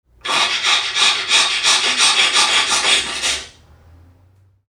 NPC_Creatures_Vocalisations_Robothead [87].wav